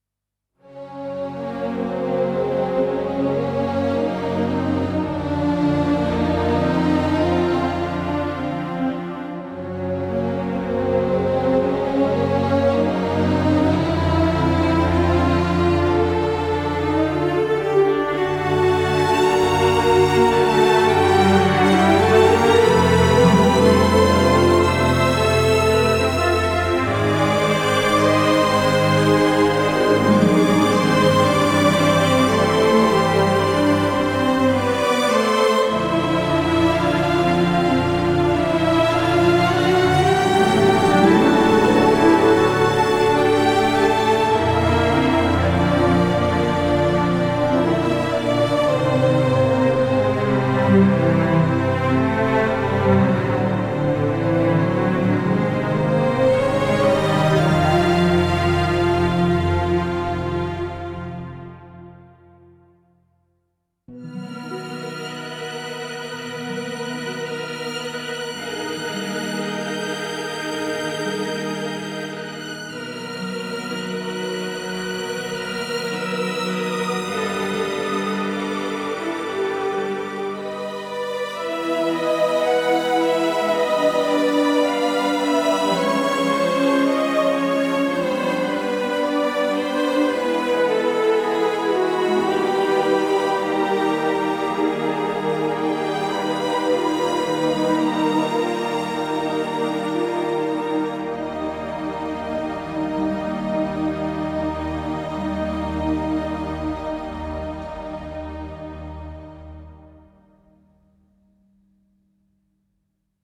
Tempo 150 BPM
aggressive background baroque blockbuster bright Cantata
choir cinematic classical dark dramatic epic film
melodic movie